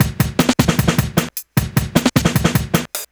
drums03.wav